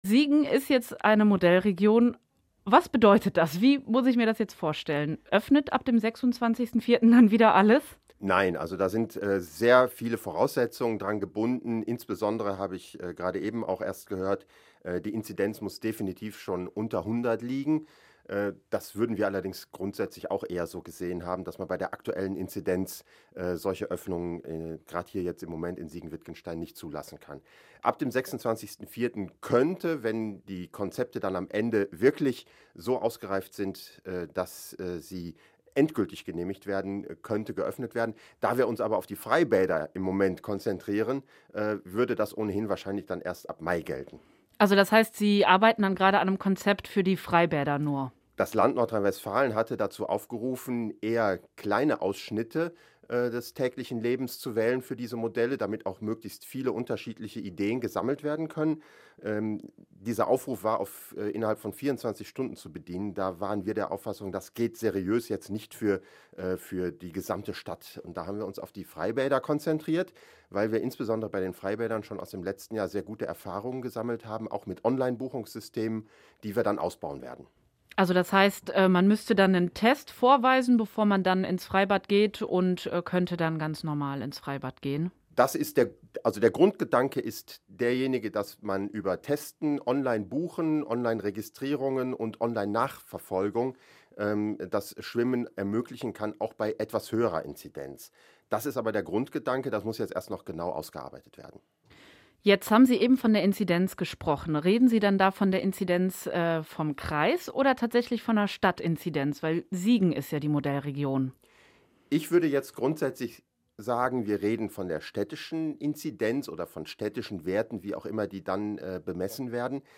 Siegen soll eine Corona Modell-Kommune werden. Siegens Bürgermeister Steffen Mues hat im Radio-Siegen-Interview